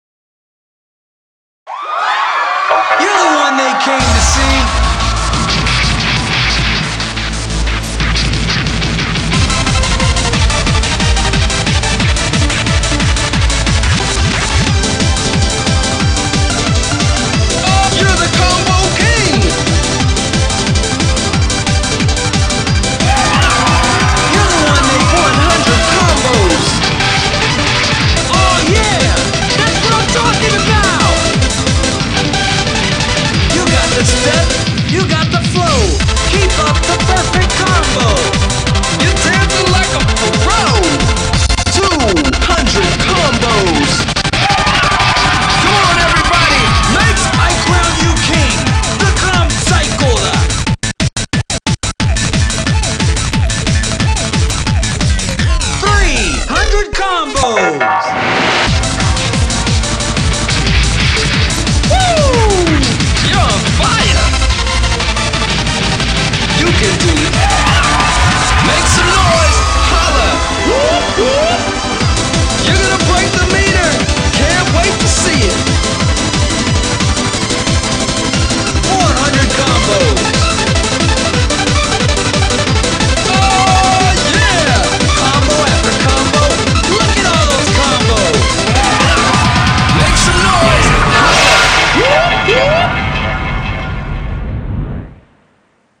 BPM74-180
Audio QualityLine Out